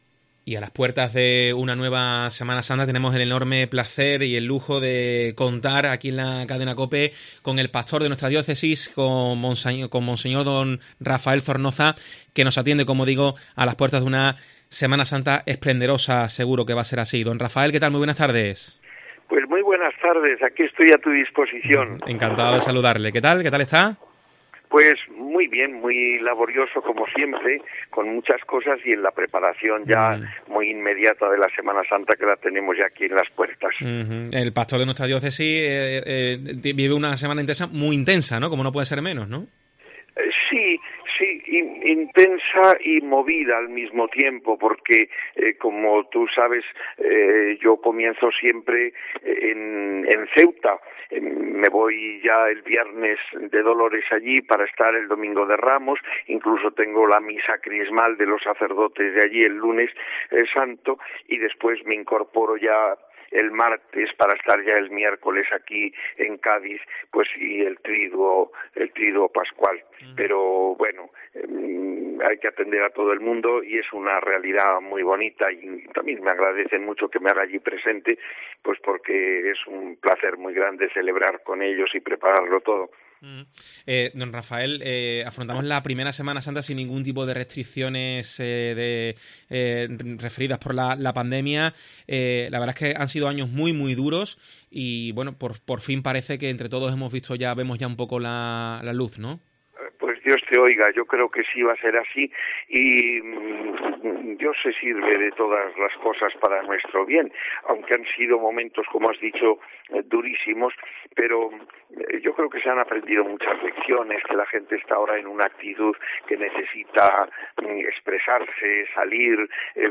ESCUCHA LA ENTREVISTA A MONSEÑOR RAFAEL ZORNOZA